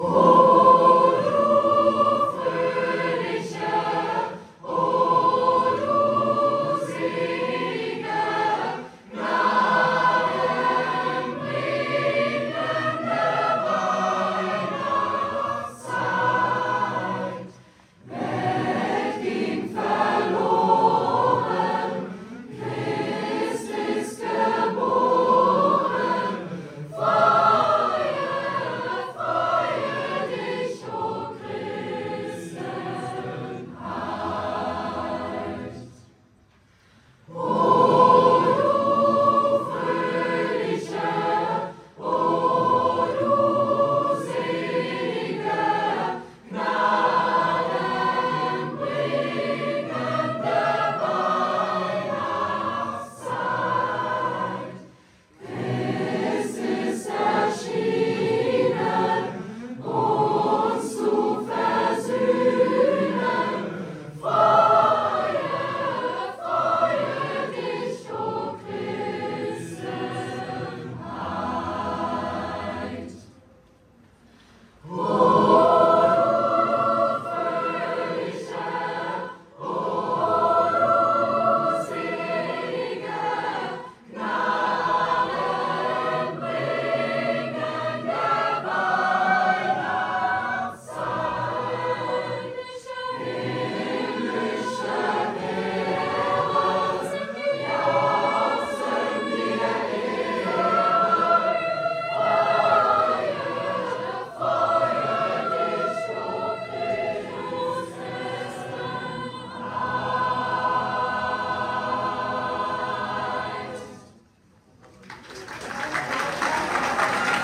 Weihnachtliches Treppenhaussingen
Jedes Jahr am letzten Schultag vor Weihnachten erfüllt sich unsere Schule mit einem ganz besonderen Klang: dem traditionellen Treppenhaussingen. Der große Chor versammelt sich und lässt stimmungsvolle Weihnachtslieder erklingen, die sich über die Stockwerke des Schulhauses ausbreiten.
So wird das gesamte Gebäude zu einem einzigen musikalischen Raum, der Wärme, Gemeinschaft und Vorfreude auf das Weihnachtsfest ausstrahlt.